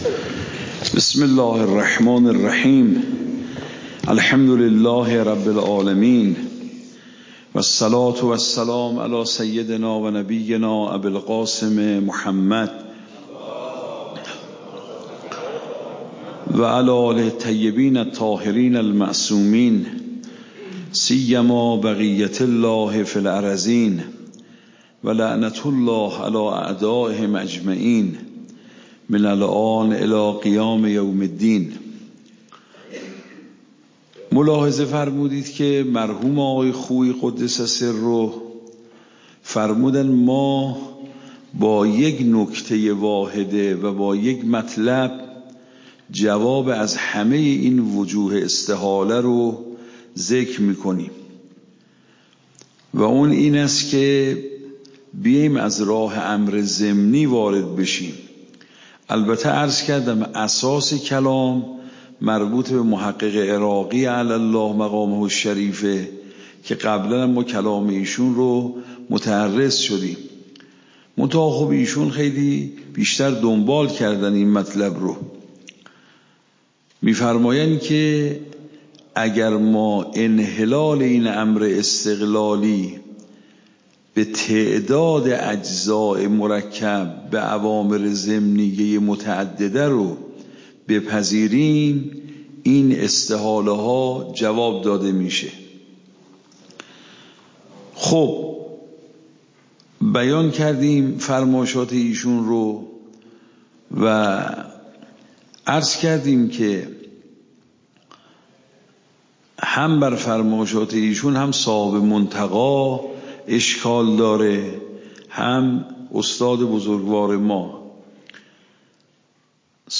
درس بعد تعبدی و توصلی درس قبل تعبدی و توصلی درس بعد درس قبل موضوع: واجب تعبدی و توصلی اصول فقه خارج اصول (دوره دوم) اوامر واجب تعبدی و توصلی تاریخ جلسه : ۱۴۰۳/۹/۶ شماره جلسه : ۳۶ PDF درس صوت درس ۰ ۴۸۲